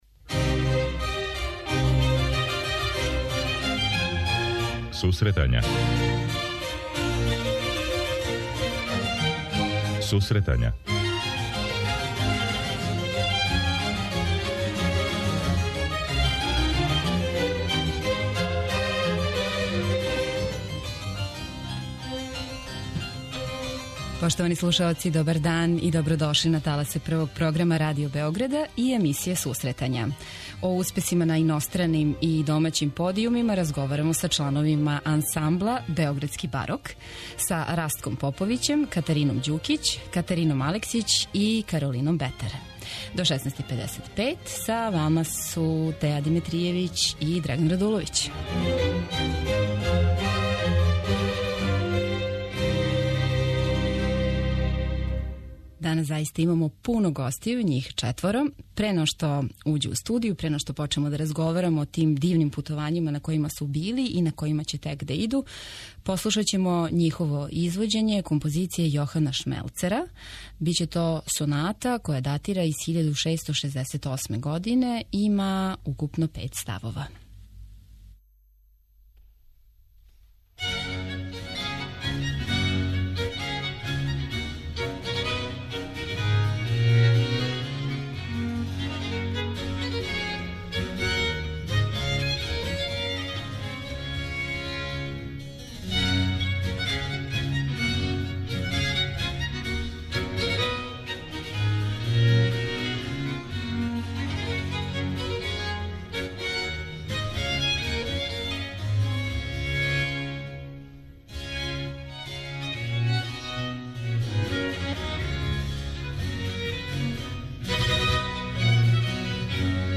У данашењој емисији разговарамо о успесима на иностраним и домаћим концертним подијумима са члановима Ансамбла Београдски барок